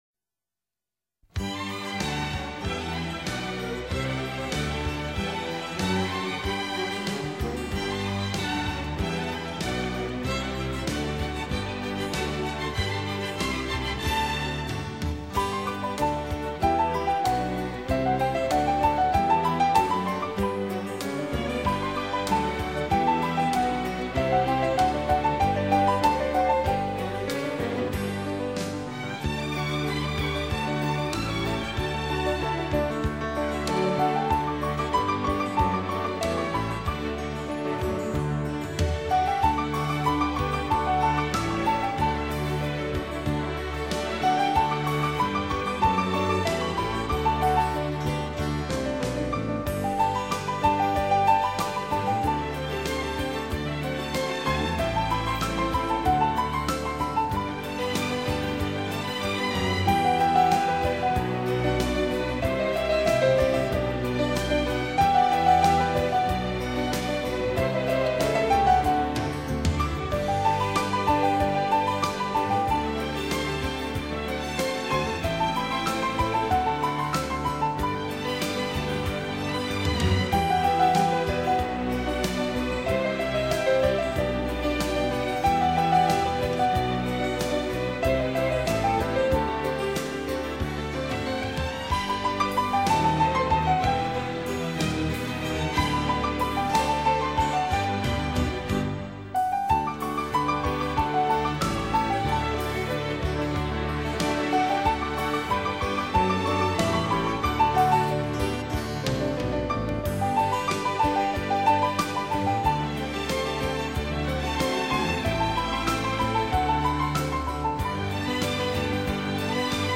手指微妙触及琴键所散发出的音符，充满罗曼蒂克式的醉人芳香将你的
流行，用行云流水般的音符阐释浪漫情怀。